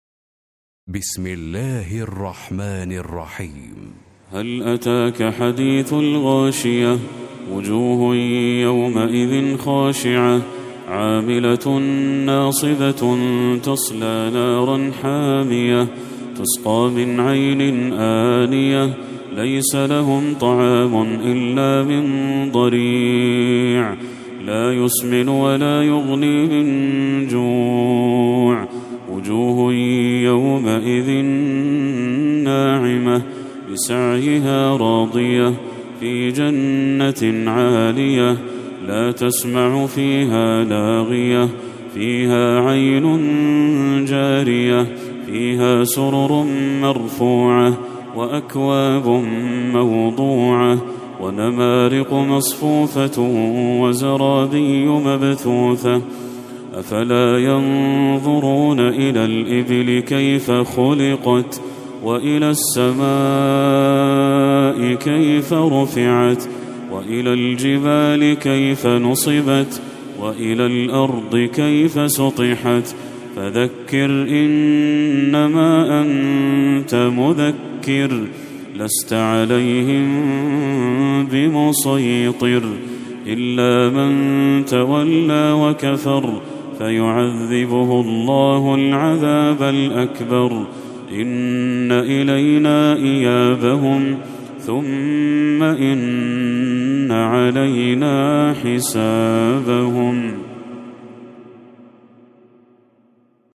سورة الغاشية Surat Al-Ghashiyah > المصحف المرتل للشيخ بدر التركي > المصحف - تلاوات الحرمين